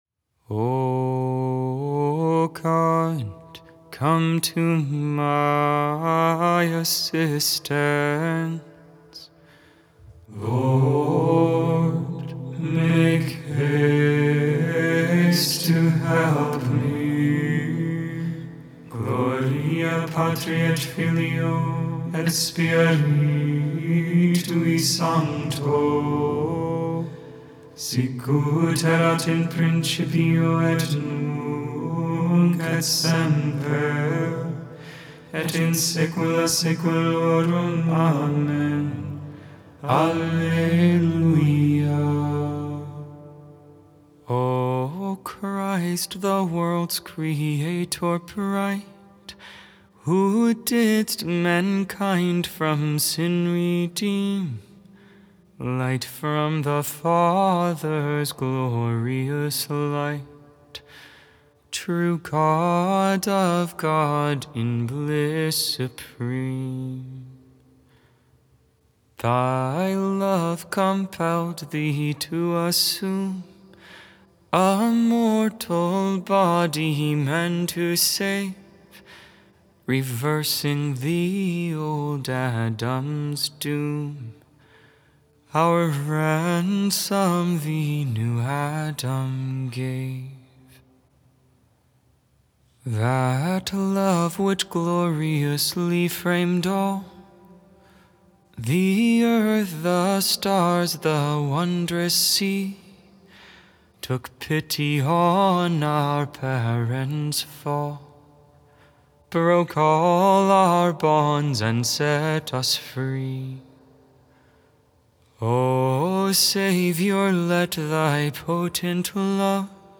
Vespers I for the Solemnity of the Sacred Heart, Evening Prayer on the 9th Thursday in Ordinary Time, June 6, 2024.